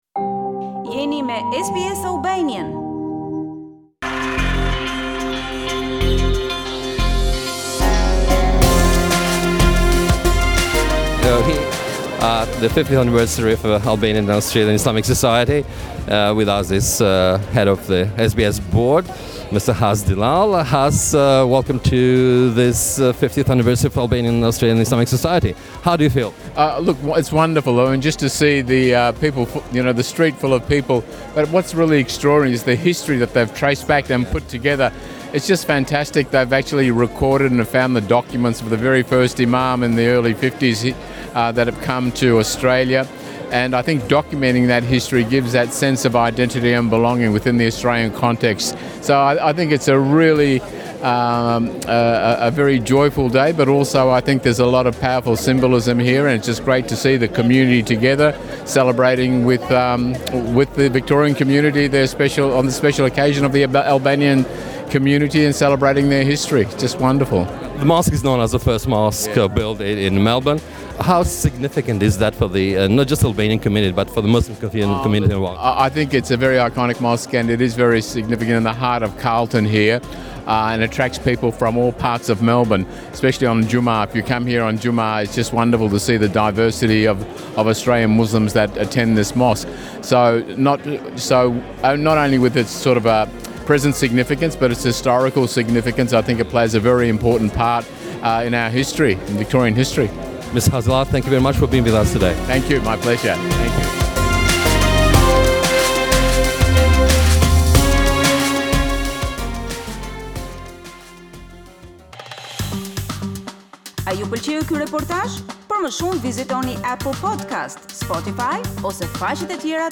Dr Hass Dellal AO, SBS Chairman at 50th Anniversary of the Albanian Mosque